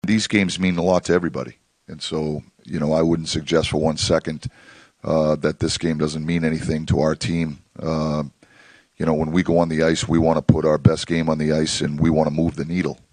Sullivan says even though there is technically nothing to gain with a win tonight over Sweden, there is still great meaning for Team USA.